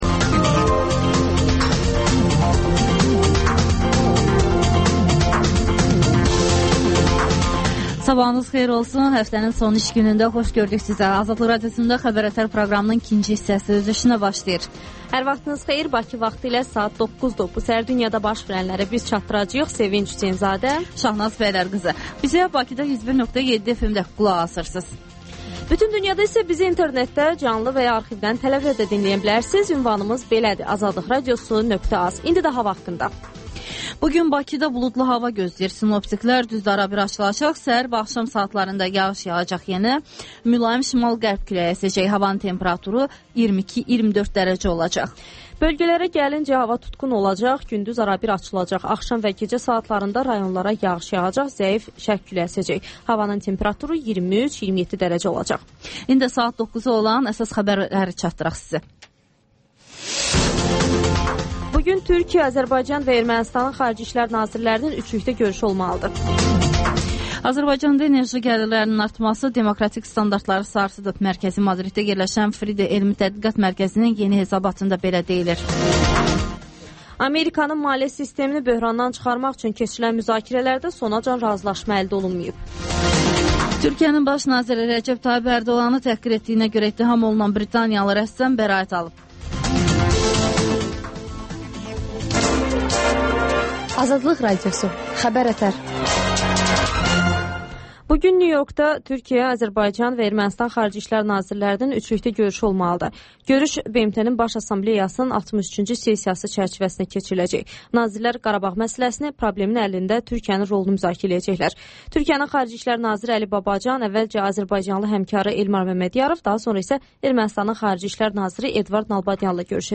Xəbər-ətər: xəbərlər, müsahibələr və 14-24: Gənclər üçün xüsusi veriliş